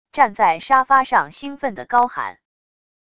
站在沙发上兴奋的高喊wav音频